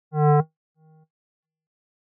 Thanks As if the sound moves away at attenuations when it vanishes it is heard in earphones.
Still it is observed as if the double sound behind as though an echo in record the test it and it is shown.